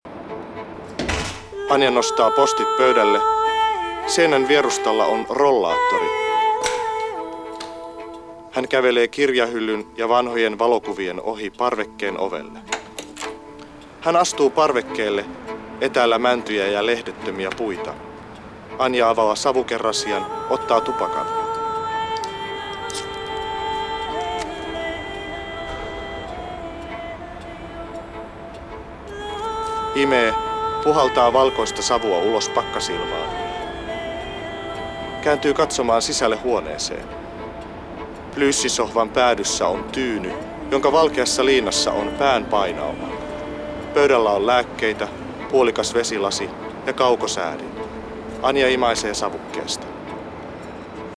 Ote Varpuset-elokuvaan tehdystä kuvailutulkkauskäsi-kirjoituksesta [MP3]
varpuset_ote_kuvailutulkkauksesta.mp3